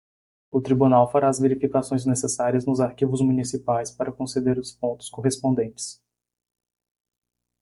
Pronounced as (IPA) /kõ.seˈde(ʁ)/